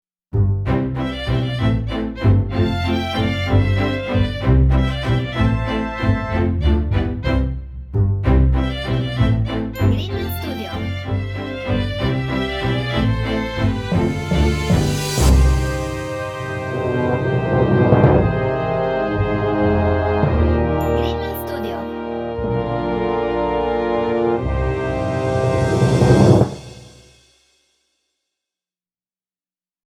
Orchestral/Cinematic